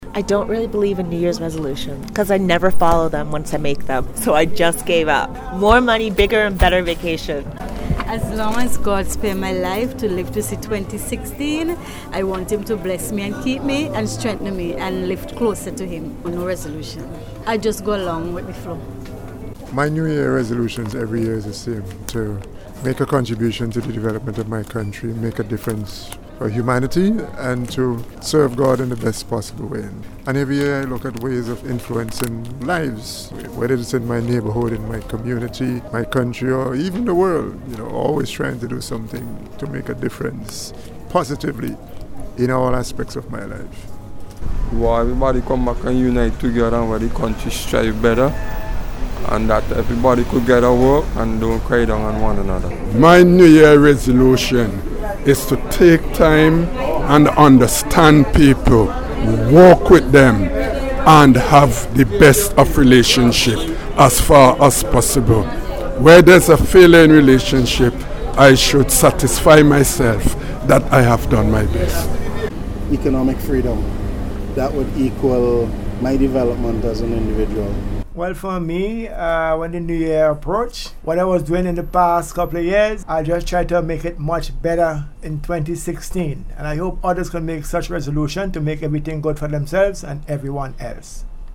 As the New Year fast approaches, NBC Radio took to the streets of Kingstown to find out what resolutions Vincentians have made for 2016. While the general consensus seemed to be that many people no longer make resolutions, there are some who carry on the tradition.